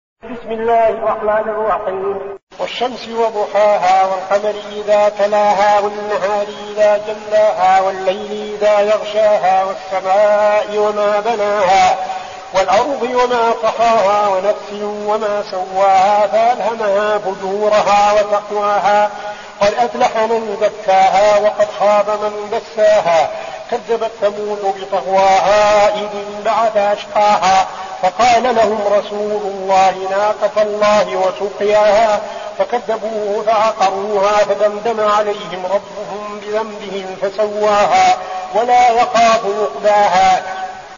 المكان: المسجد النبوي الشيخ: فضيلة الشيخ عبدالعزيز بن صالح فضيلة الشيخ عبدالعزيز بن صالح الشمس